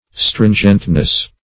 stringentness - definition of stringentness - synonyms, pronunciation, spelling from Free Dictionary